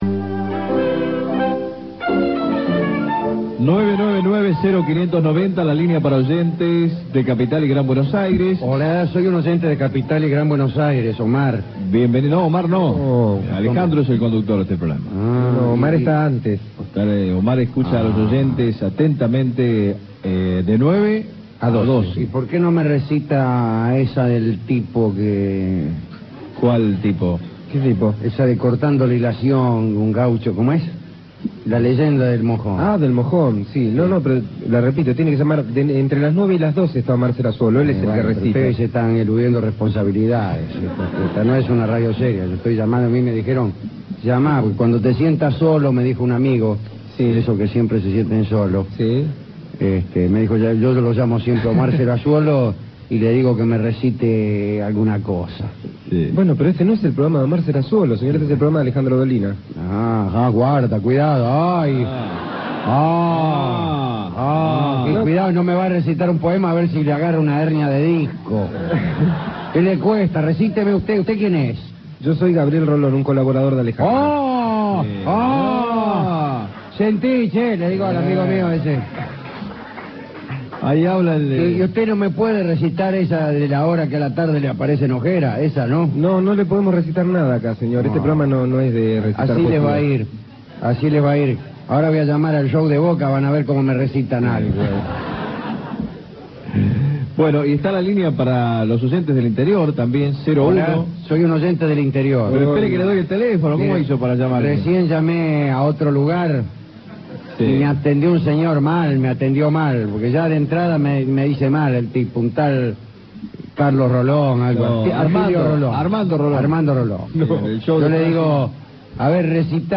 Estudios de Radio Rivadavia (AM 630 kHz), 1989